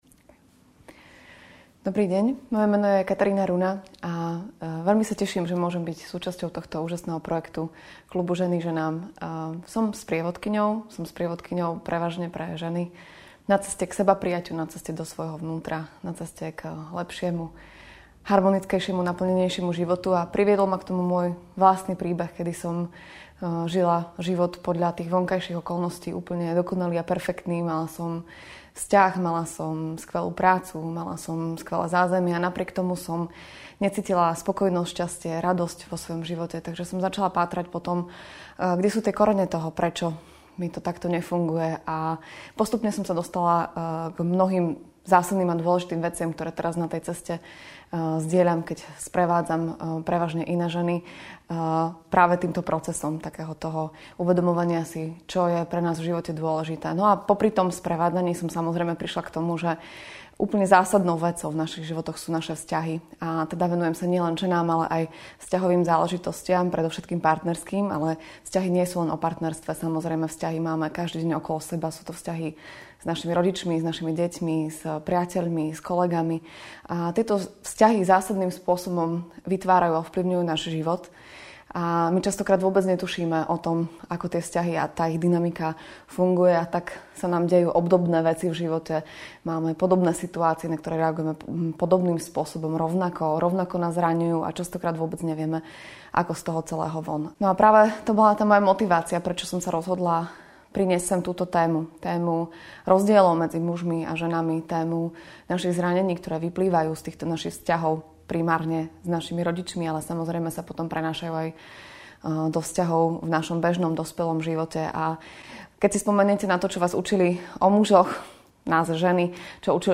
V tejto prednáške sa pozrieme na základné rozdiely medzi mužským a ženským princípom a objasníme si mnoho nepochopenia, ktoré s týmito rozdielmi do nášho života prichádza. Povieme si o tom, ako časté narušenie trojuholníka otec- matka - dieťa vplýva na mužov aj ženy ešte aj v dospelosti.